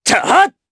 Lucias-Vox_Attack2_jp.wav